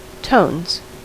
Ääntäminen
Ääntäminen US RP : IPA : /təʊnz/ GenAm: IPA : /toʊnz/ Haettu sana löytyi näillä lähdekielillä: englanti Kieli Käännökset ranska camaïeu Tones on sanan tone monikko.